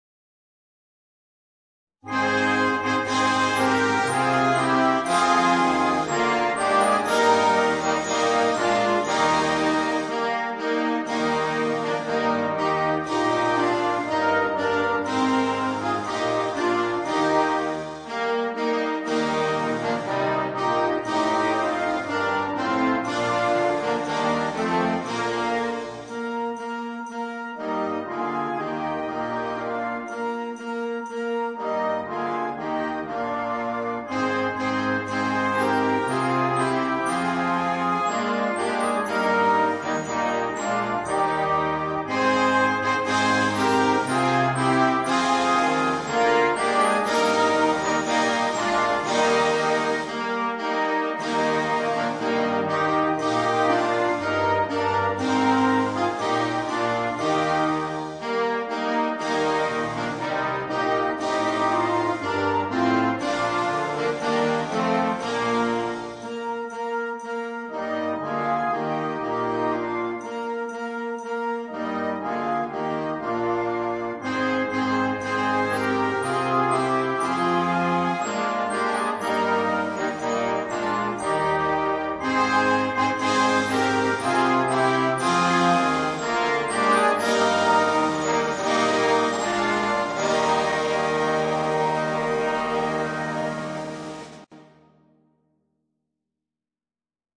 BRANI DI NATALE